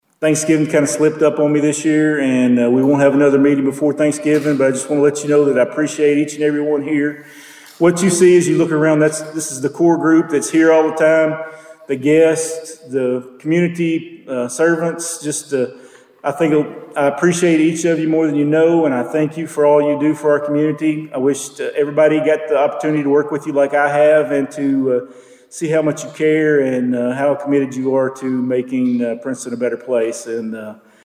Mayor Thomas also shared another message before the meeting was adjourned: